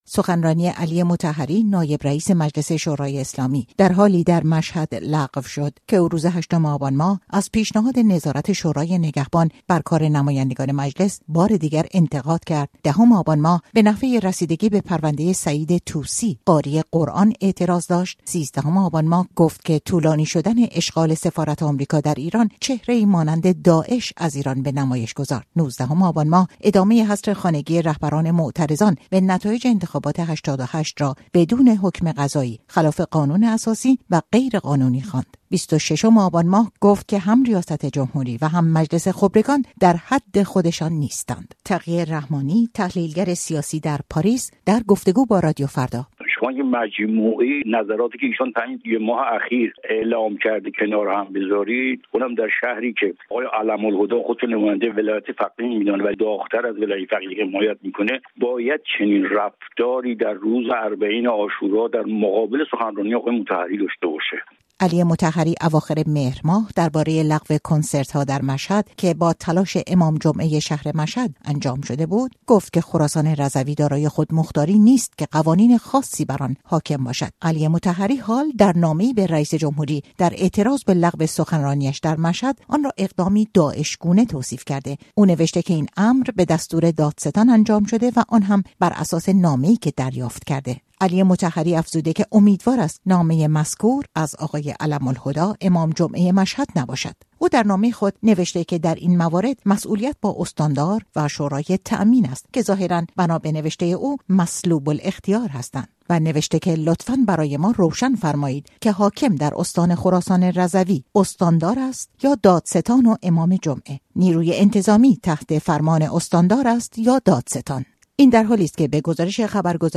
علی مطهری، در نامه‌ای به حسن روحانی رئیس جمهور ایران، لغو سخنرانی‌اش در مشهد را «داعش‌گونه» توصیف کرده و تلویحاً به نقش امام جمعه در آن اشاره کرده است اما احمد علم‌الهدی هر گونه اطلاع و یا ارتباط با این موضوع را رد کرده است. گزارشی را در همین مورد بشنوید.